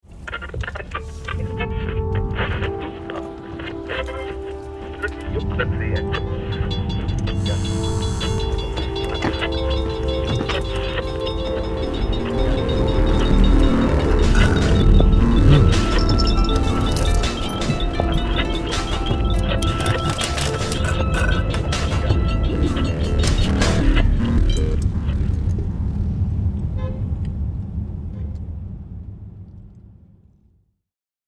Un gángster en su auto con ambiente turbio, quien trata de mostrar lujos y jugar un papel excéntrico. Toques de música asiática para reforzar el origen del personaje.